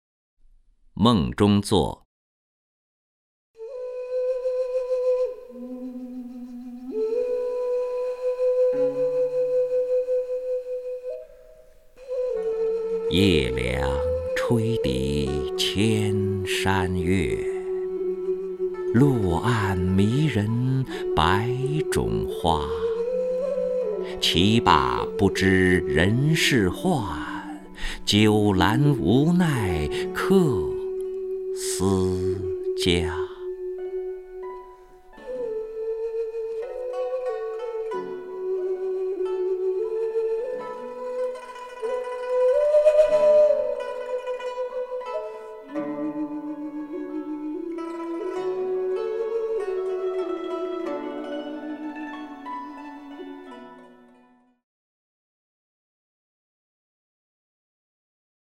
任志宏朗诵：《梦中作》(（北宋）欧阳修) （北宋）欧阳修 名家朗诵欣赏任志宏 语文PLUS